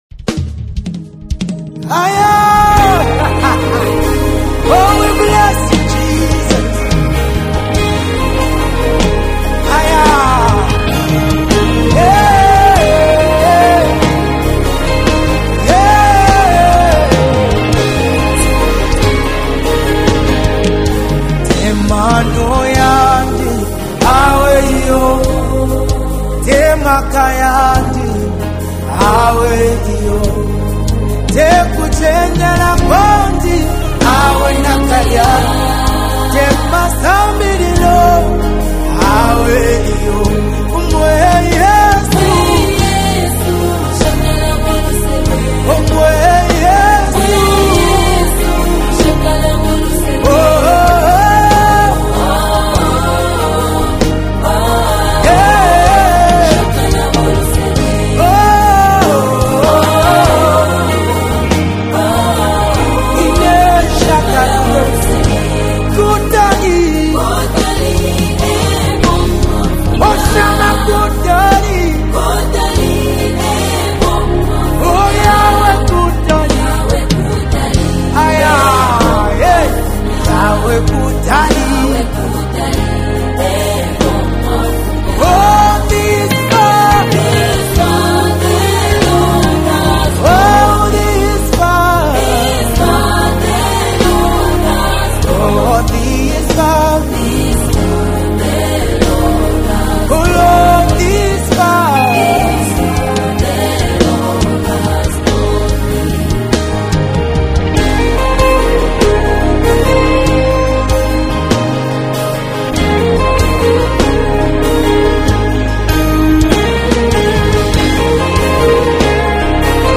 🎼 GENRE: ZAMBIAN GOSPEL MUSIC